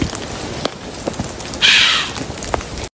fdc63-cri-animal-daj45w1.mp3